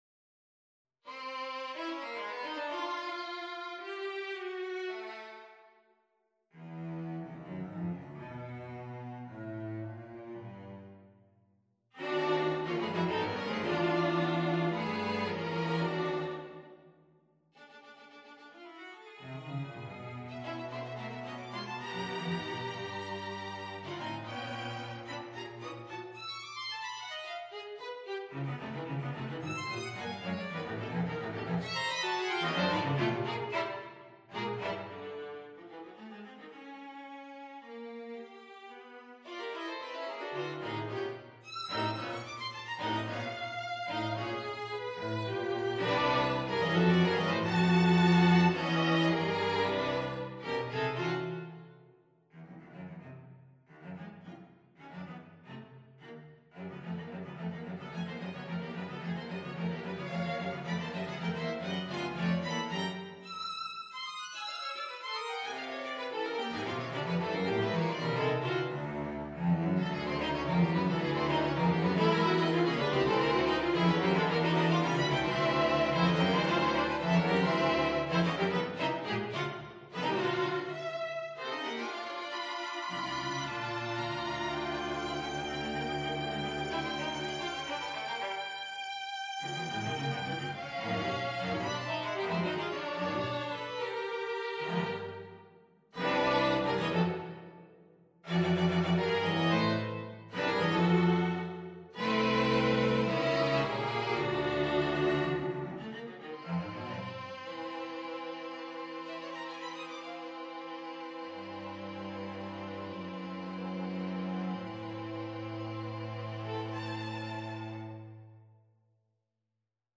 Quartet for Strings No.15, Op.120
Quartet for Strings No.15 on a purpose-selected tone row Op.120 1.